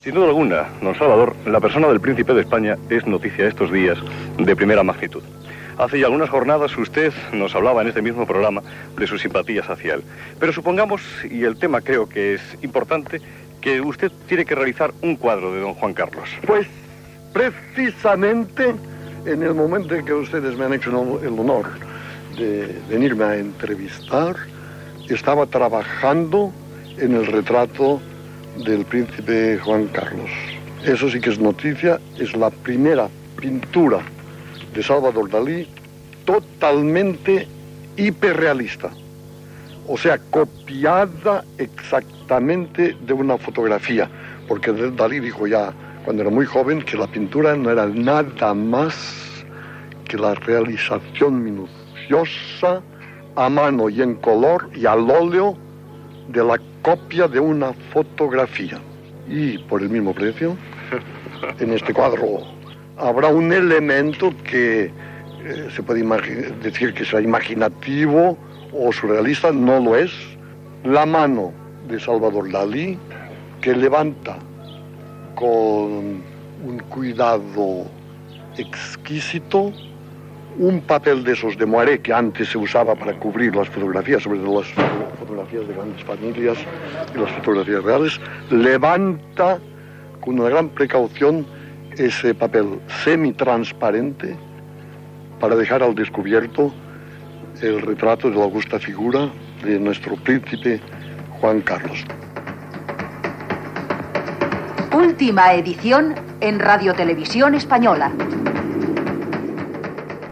Programa realitzat des de la casa de Salvador Daí a Port Lligat.
Informatiu